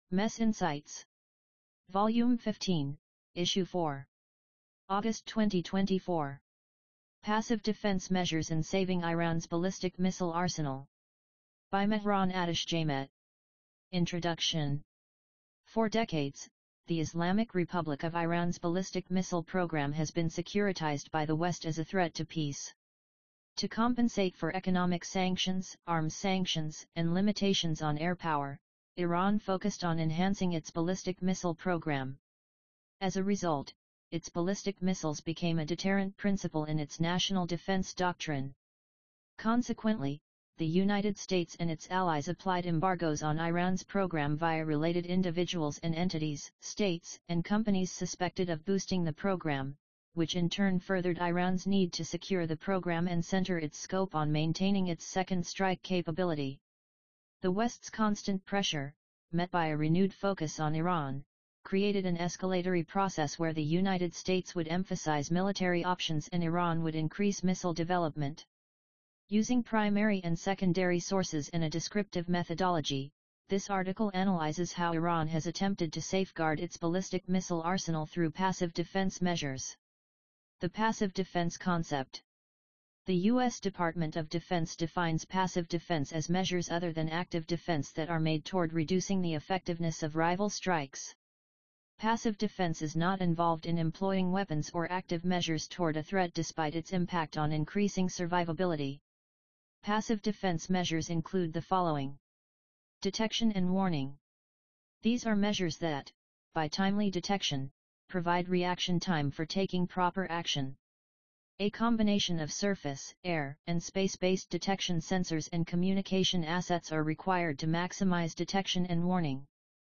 MES insights 15_4_Atashjemah_AUDIOBOOK.mp3